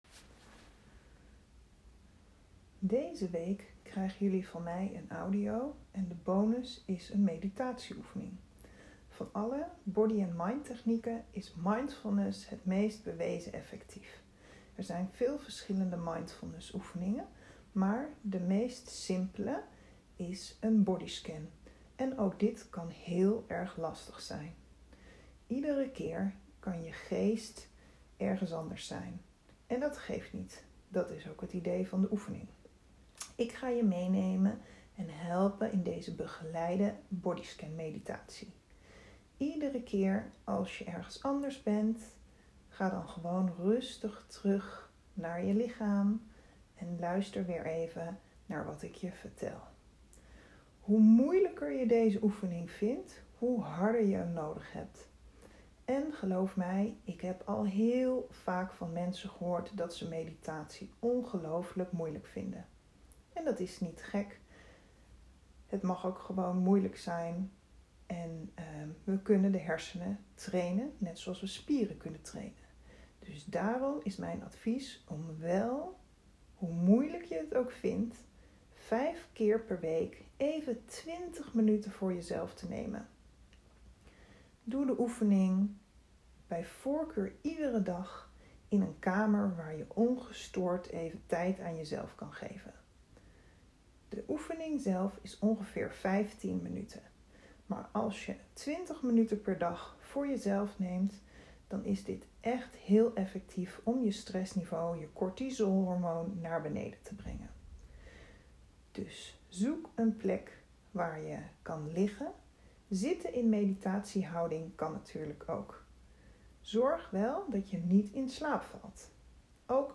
Klik HIER voor een korte bodyscan meditatie die ik voor jullie heb ingesproken.